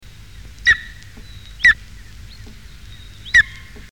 Aigle royal
Aquila chrysaetos
aigle.mp3